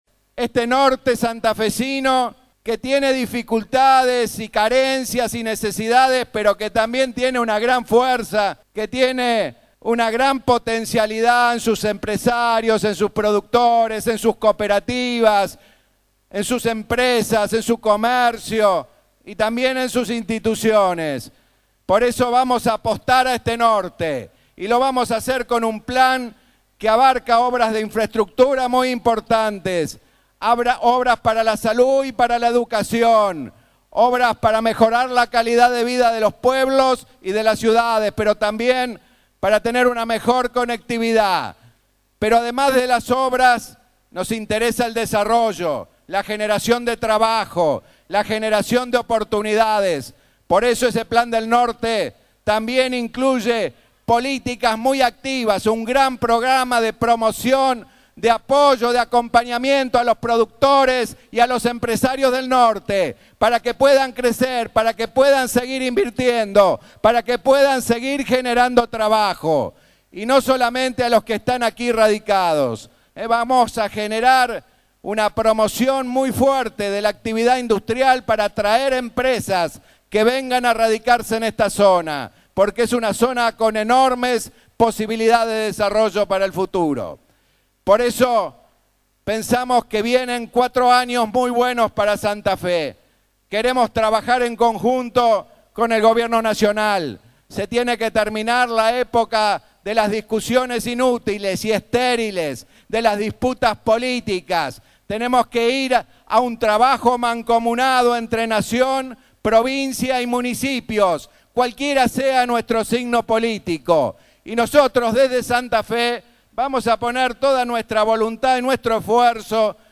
El gobernador presidió este domingo los actos en conmemoración del 137º aniversario fundacional de la ciudad.
La celebración se realizó en la plaza de la ciudad y contó con la presencia del intendente, Dionisio Scarpín, los ministros de la Producción y Medio Ambiente, Luis Contigiani y Jacinto Speranza, respectivamente;  el senador provincial, Orfilio Marcon, la diputada provincial Clara García, intendentes y presidentes de comuna de la región, y un numeroso público que se hizo presente para el evento.